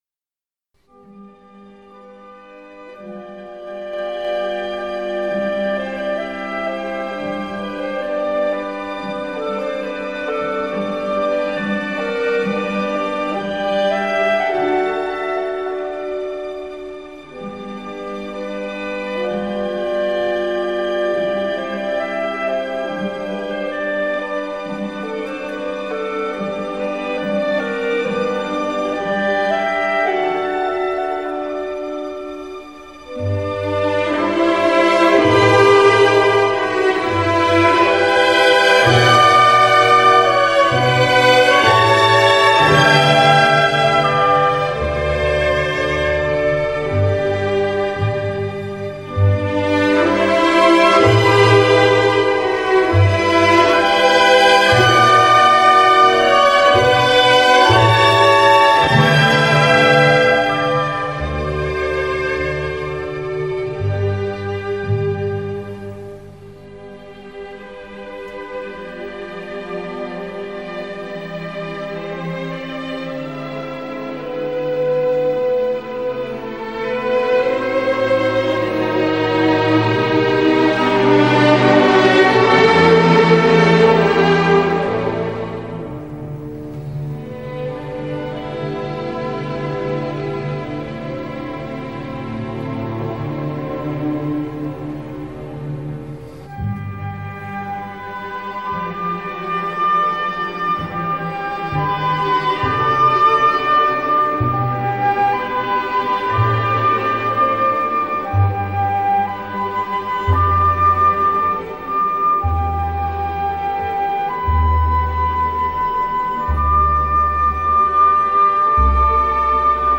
پایان سمفونی هشت دوژاک به رهبری کارایان